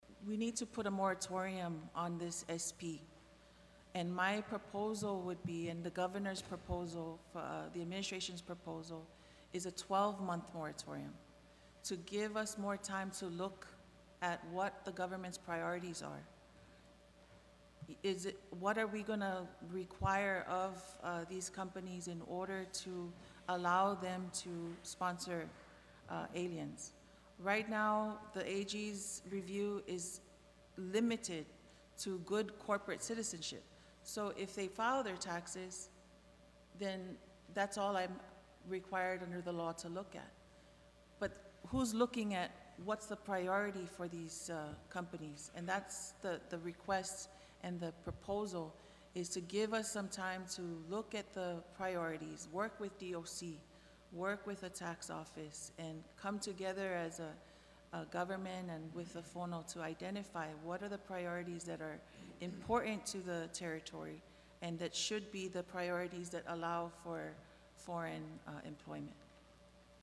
Speaking at a hearing of the Senate Government Operations Committee on Friday, the AG said a review of applications from corporations over a six month period most business plans that are submitted are for auto shops and sewing shops.